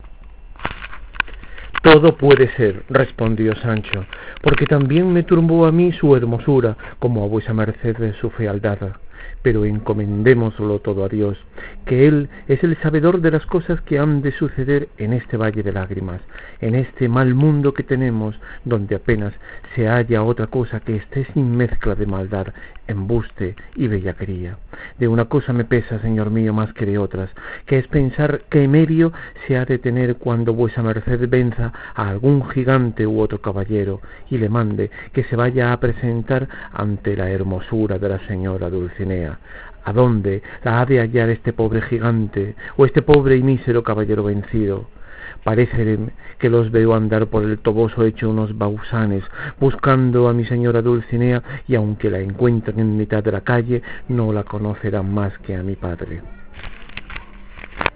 En la IX Lectura continuada de El Quijote
Lectura en vivo
Voz El Quijote.WAV